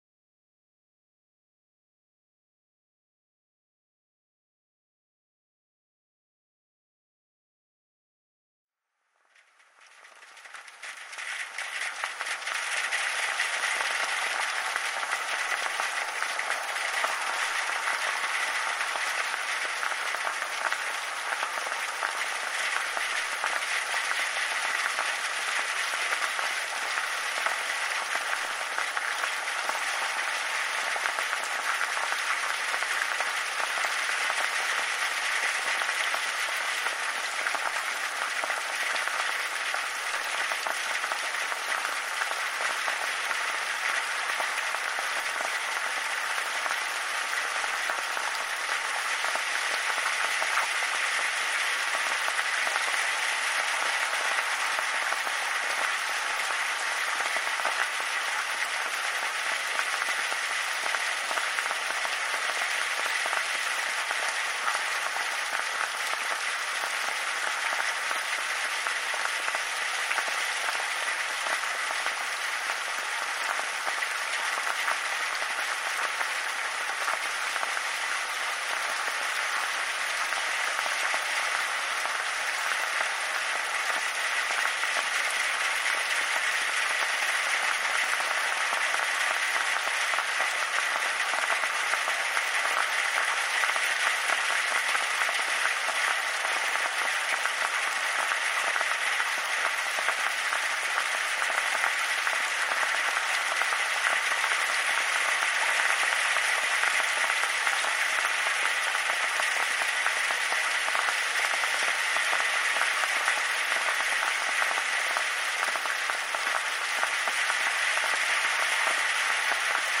【リラックス】眠れるノイズ 1時間｜夏の雨で深い眠り
時には水音、時には遠雷、時には耳の中の宇宙のようなノイズ。